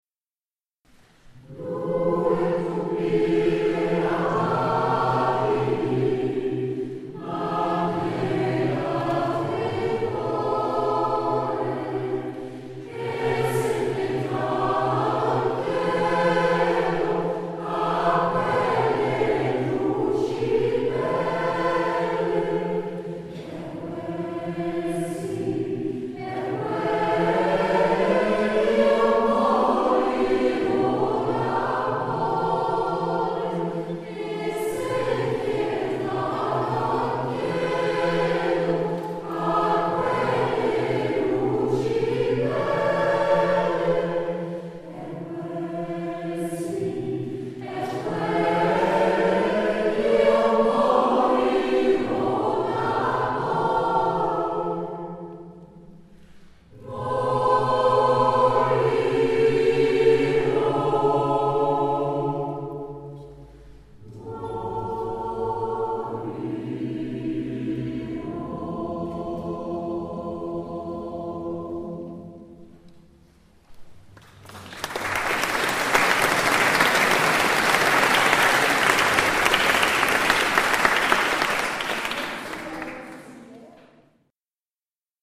Quelques enregistrements effectués lors de nos concerts